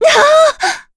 Yuria-Vox_Damage_kr_04.wav